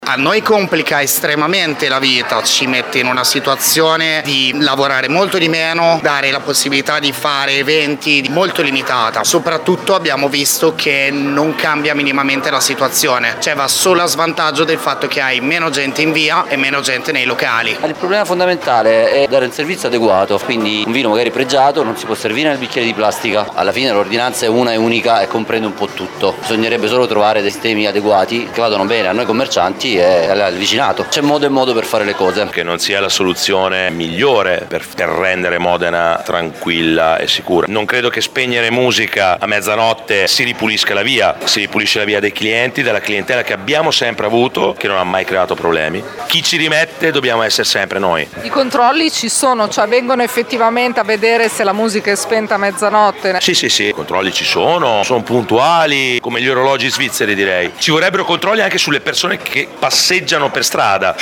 Qui sotto le interviste realizzate in via Gallucci tra i gestori: